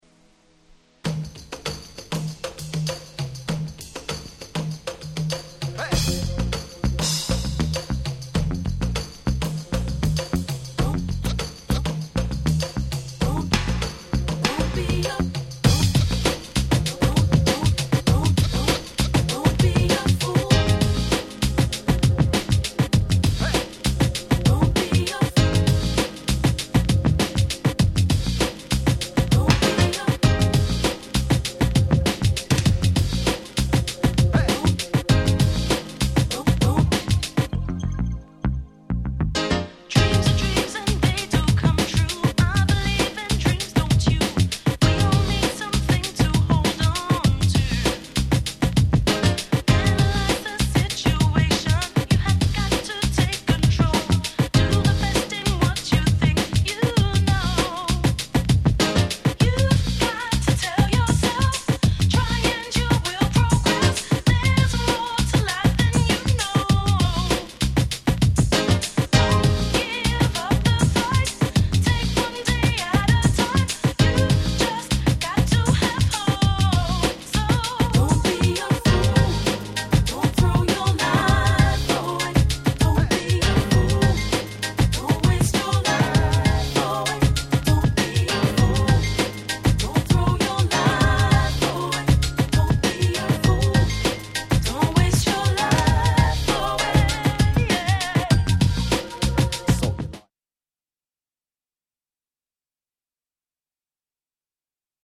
UK Soul Classics !!